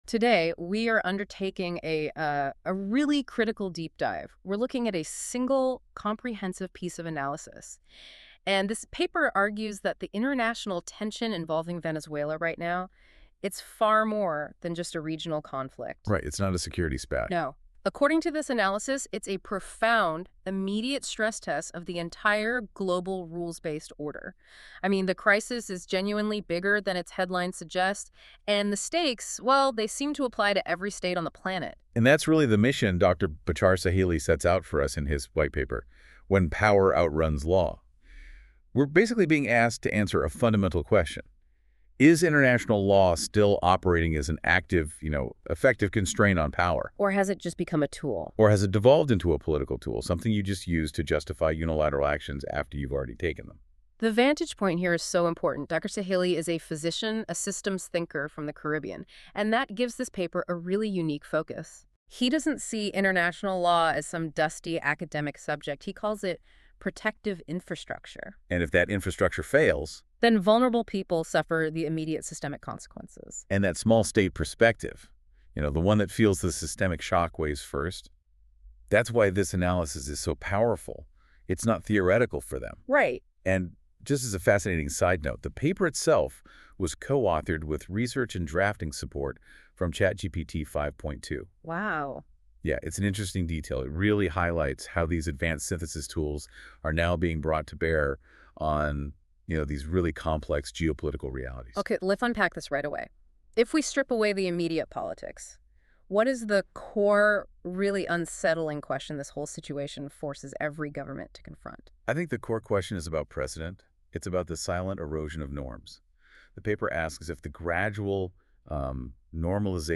Deep Diver Audio Overview